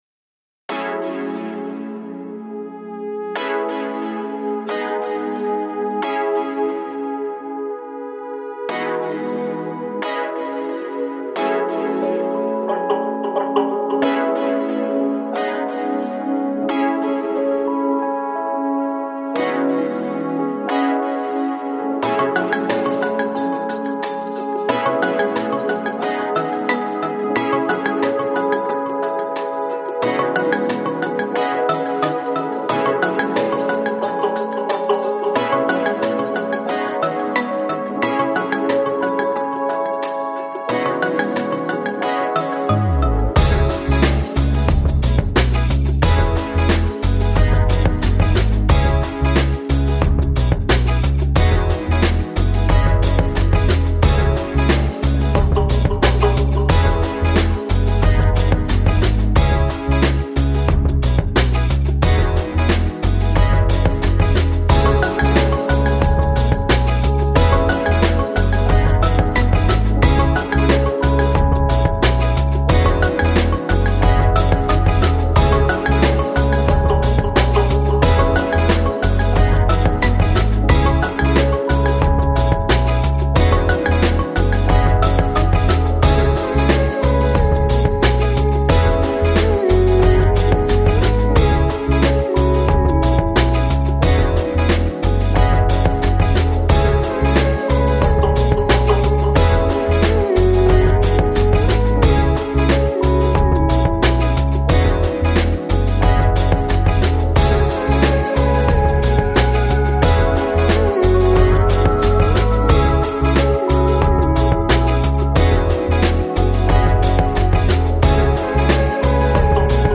Lounge, Balearic, Downtempo, Smooth Jazz